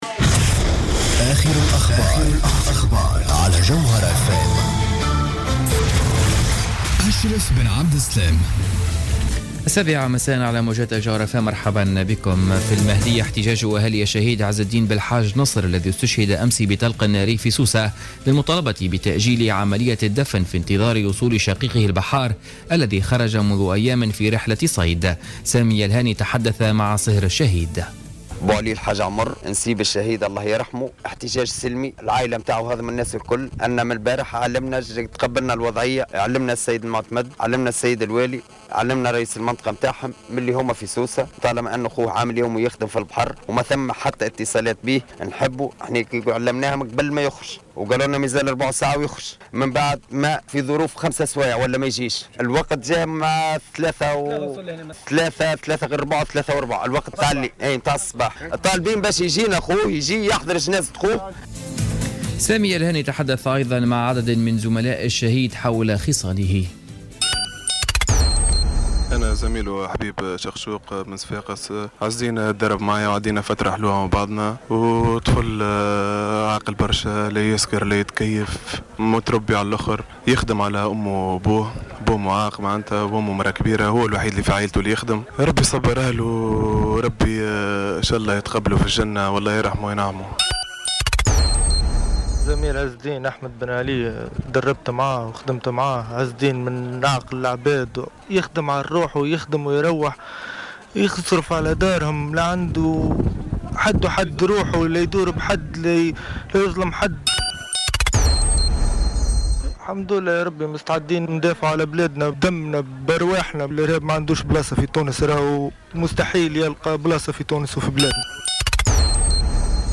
نشرة أخبار السابعة مساء ليوم الخميس 20 أوت 2015